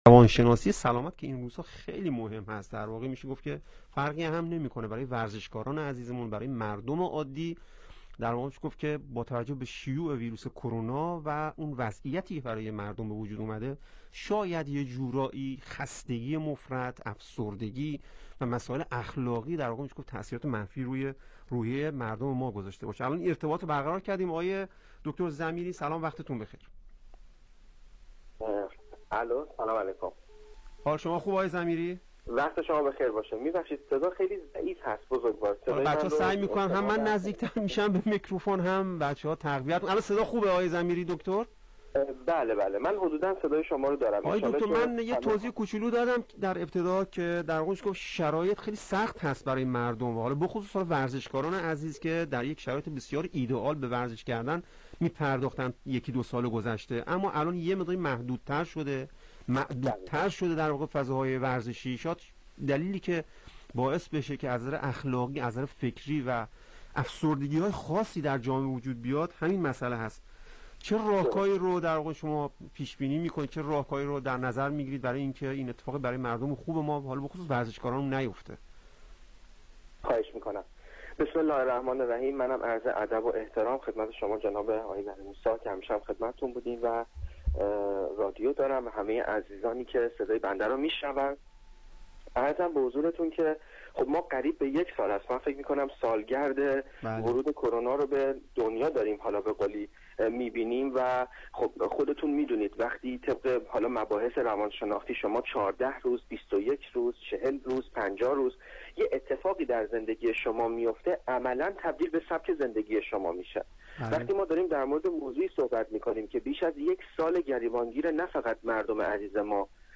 در برنامه رادیو خوزستان در خصوص سلامت روان جامعه با شیوع بیماری کرونا انگلیسی نکاتی را بیان کرد.